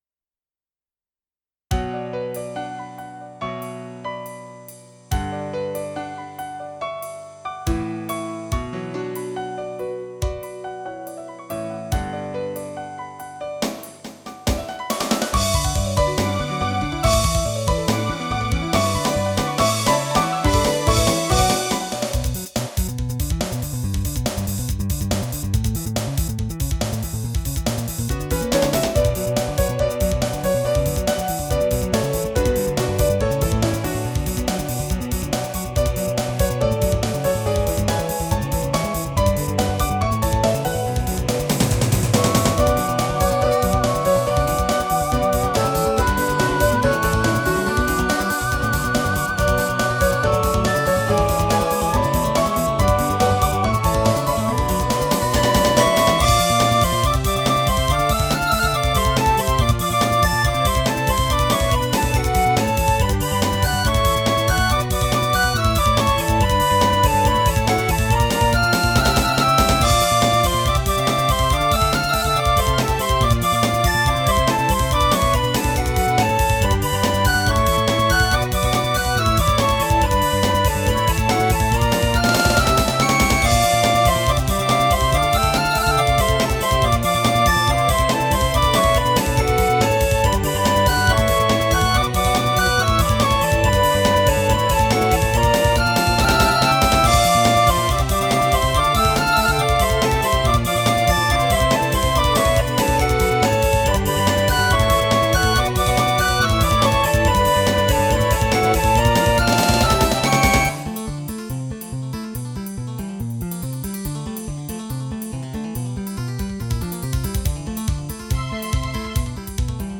由Roland Sound Canvas 88Pro实机录制；MP3采样率44.1KHz，码率192Kbps。